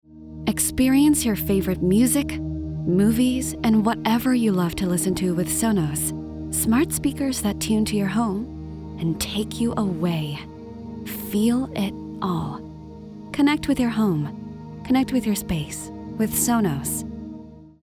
commercial
smooth
warm